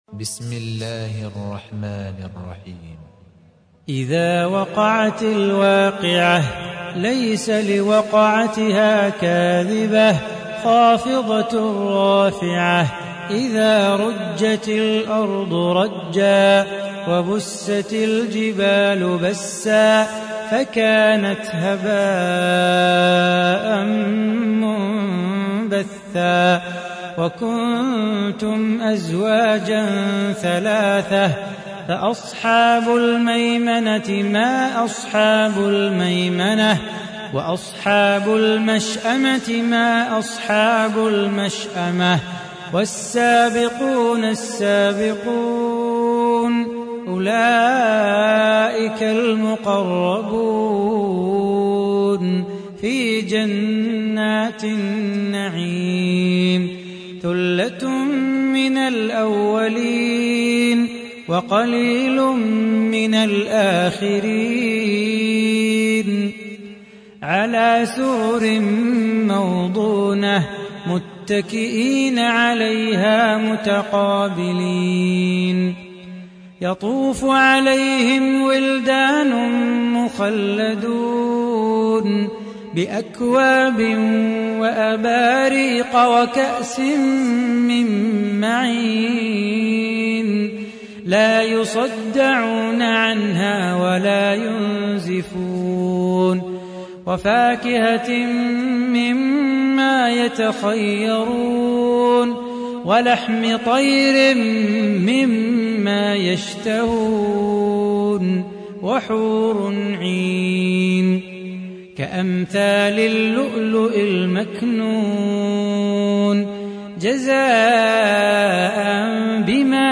تحميل : 56. سورة الواقعة / القارئ صلاح بو خاطر / القرآن الكريم / موقع يا حسين